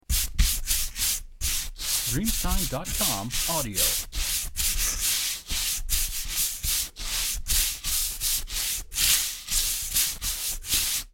Brushing Fast 2
• SFX